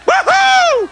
Sound Effects for Windows
whawhoo1.mp3